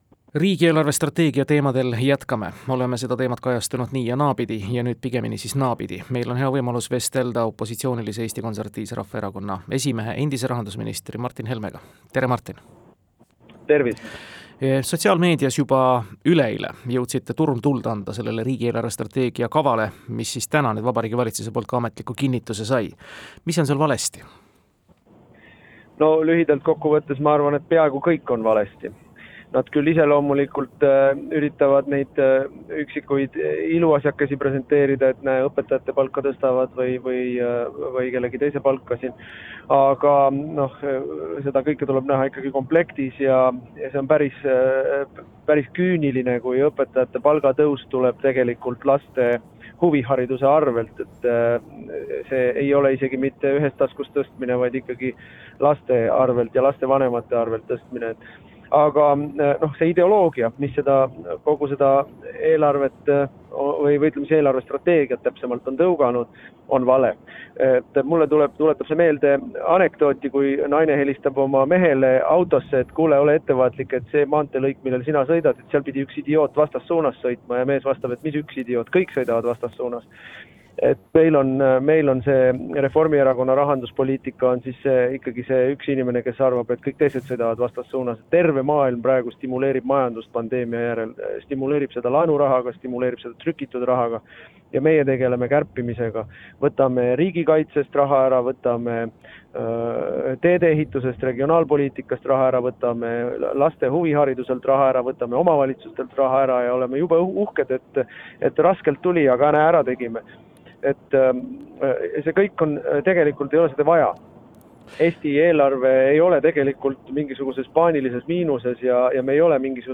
Intervjuu: Martin Helmega